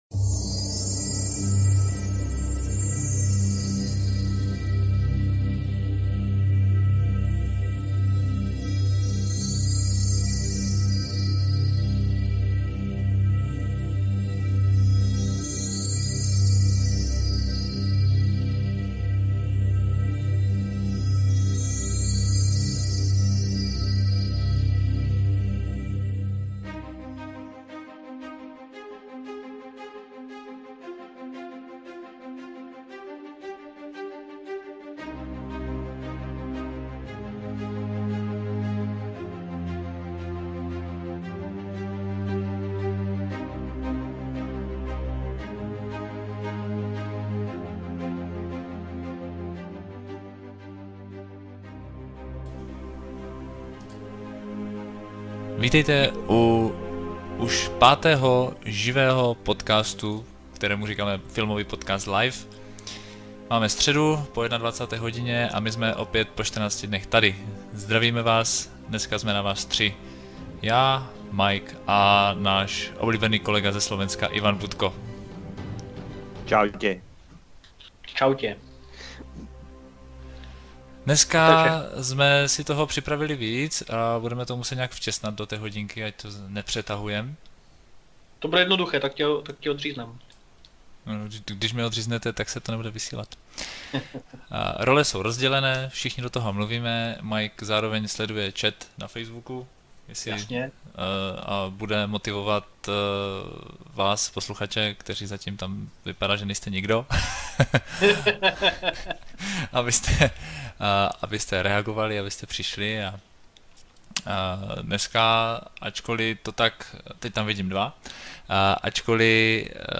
Středeční živé vysílání se dost věnovalo tématům kolem Batmanovských filmů, ale u toho to nezůstalo.